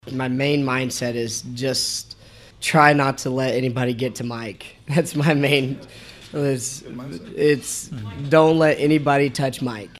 weekly radio show at Dink’s Pit BBQ